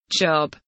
job kelimesinin anlamı, resimli anlatımı ve sesli okunuşu